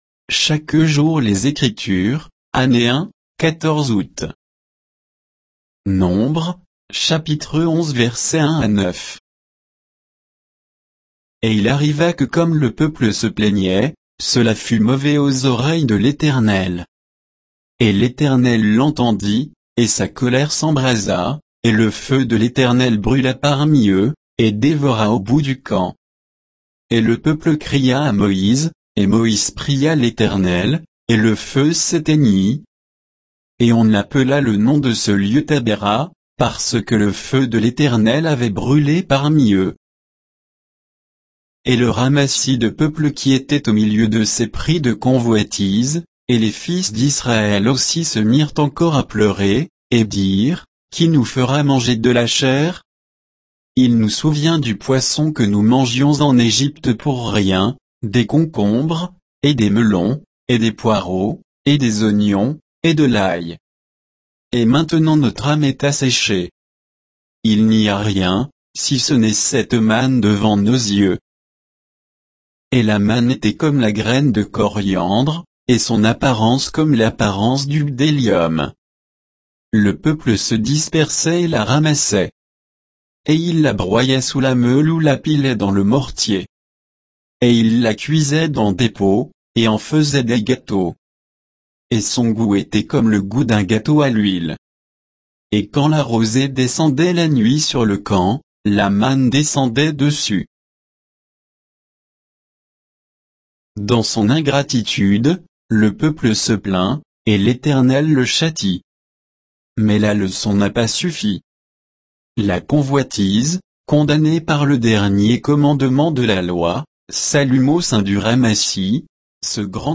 Méditation quoditienne de Chaque jour les Écritures sur Nombres 11, 1 à 9